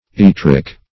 yttric - definition of yttric - synonyms, pronunciation, spelling from Free Dictionary Search Result for " yttric" : The Collaborative International Dictionary of English v.0.48: Yttric \Yt"tric\, a. (Chem.) Pertaining to, derived from, or containing, yttrium.